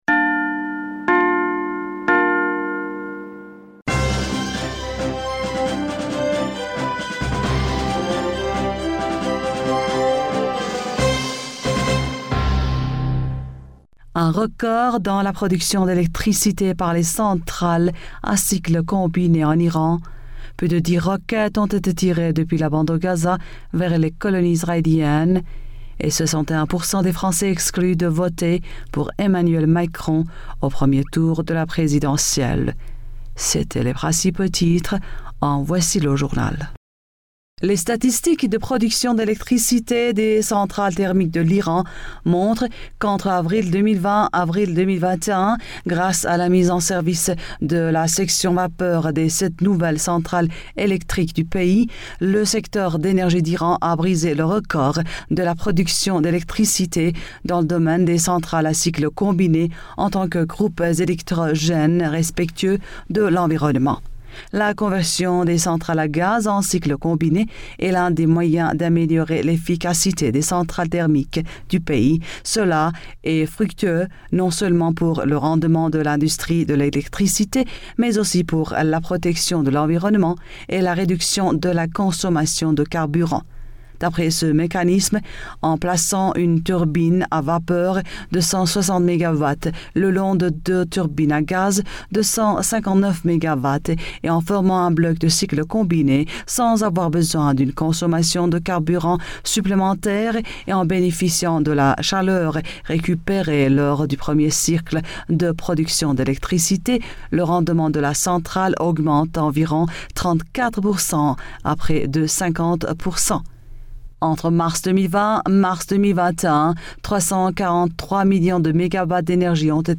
Bulletin d'information du 24 Avril 2021